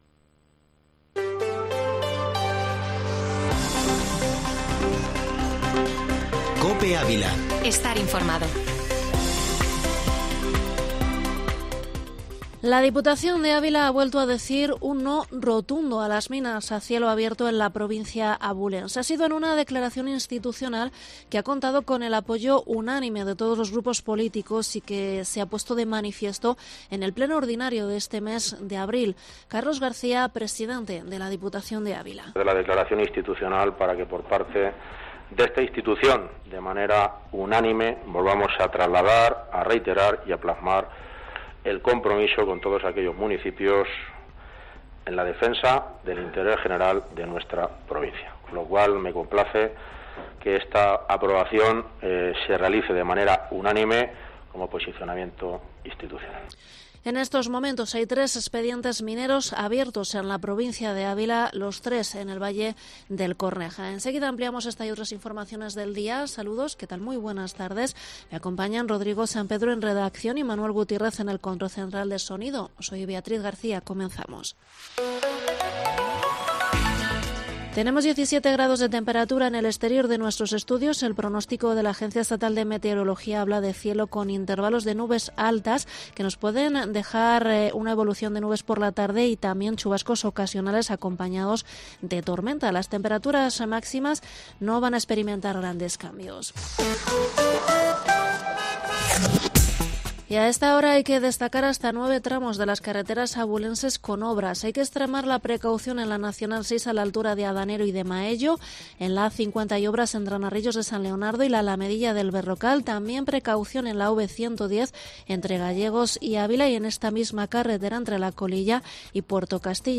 Informativo Mediodía Cope en Avila 26/4/22